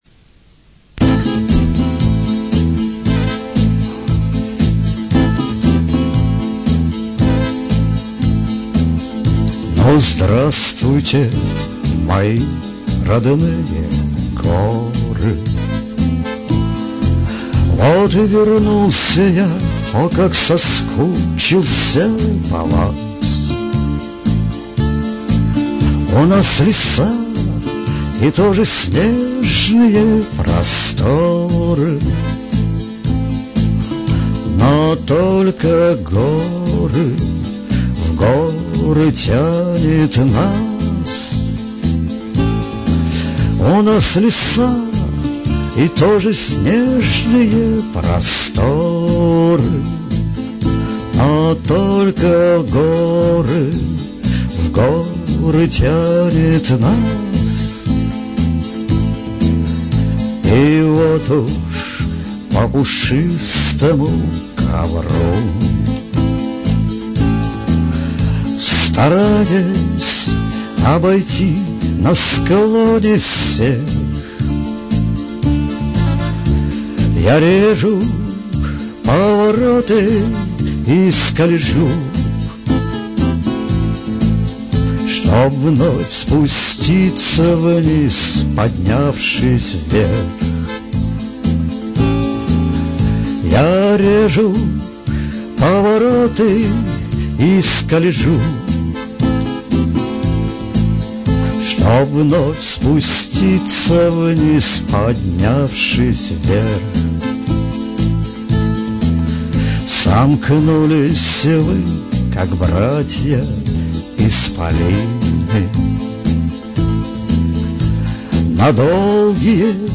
НЕ ШАНСОН!!Авторская песенка...слушай спокойно, ув. лыжник